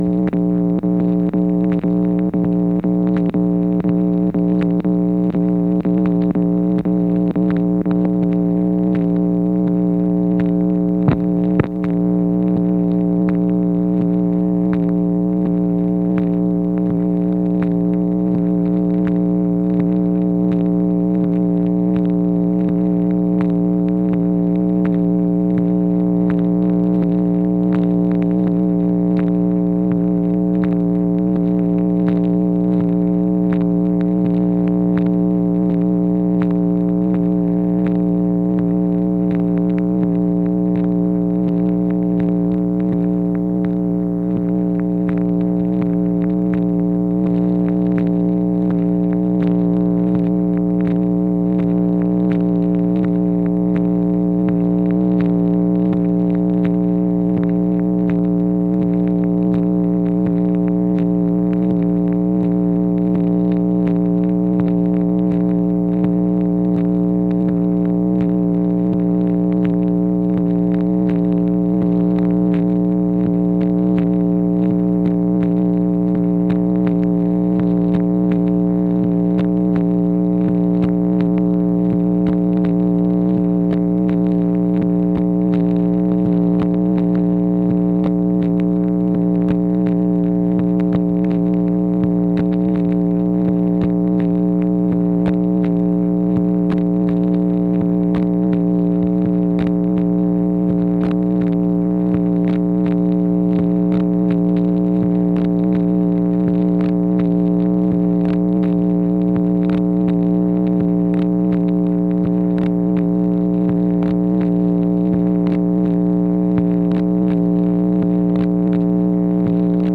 MACHINE NOISE, June 11, 1964
Secret White House Tapes | Lyndon B. Johnson Presidency